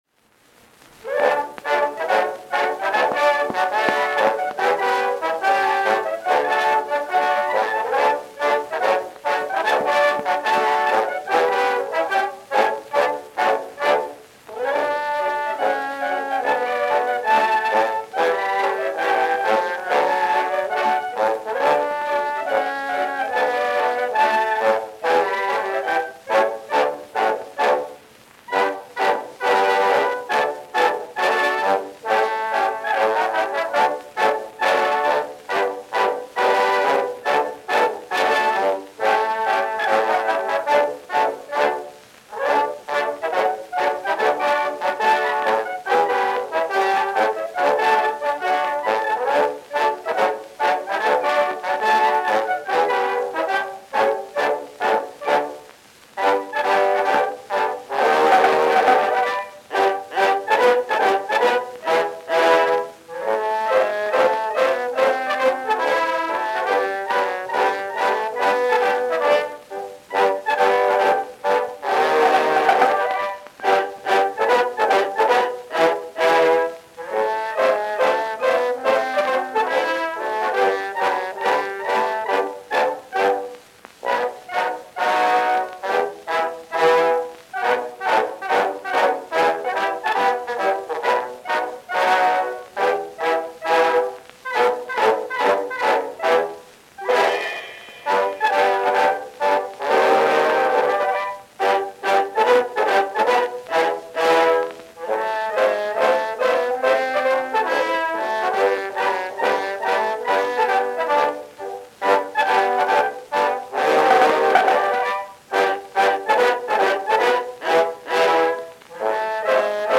Dance music.
Band music.
Popular instrumental music—1911-1920.